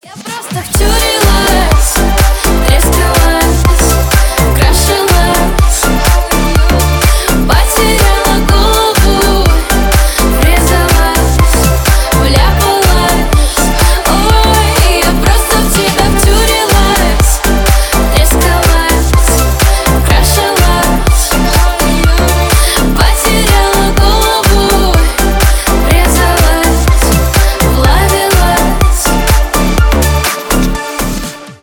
Ремикс # Поп Музыка
тихие